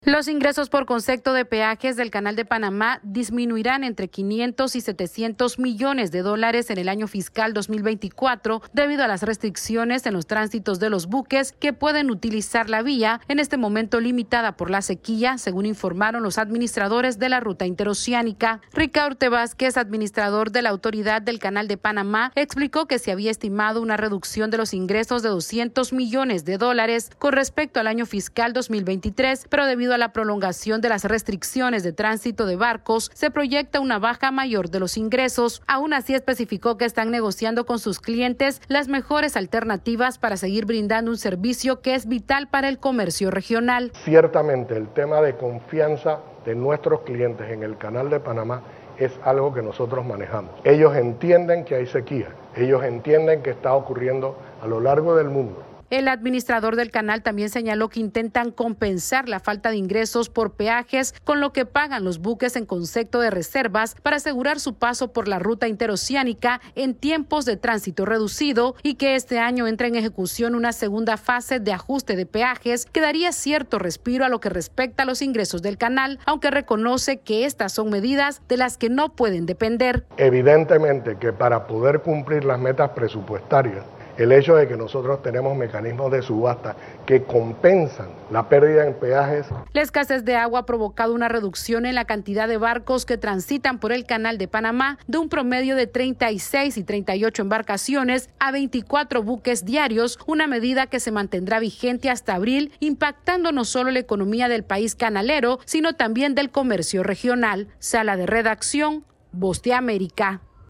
Los encargados del Canal de Panamá estiman pérdidas de hasta 700 millones de dólares este año debido a la persistente crisis hídrica. Esta es una actualización de nuestra Sala de Redacción...